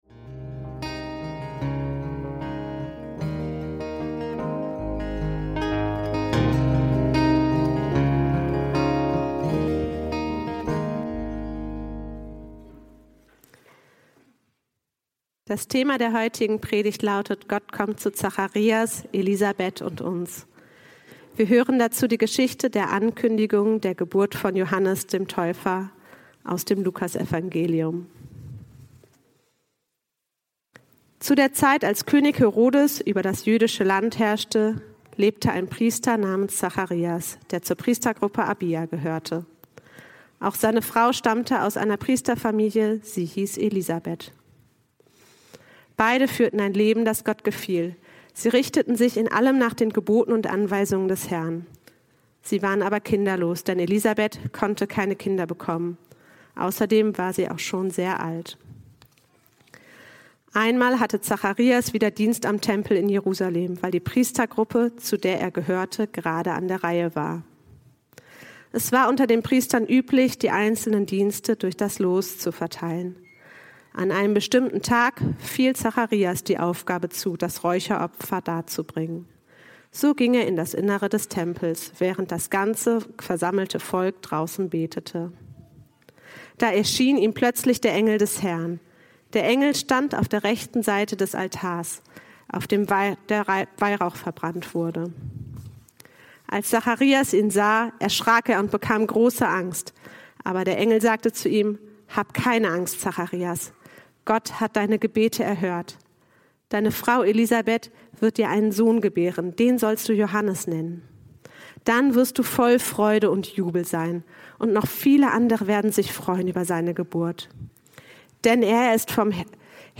Gott kommt zu Zacharias, Elisabet und uns – Predigt vom 01.12.2024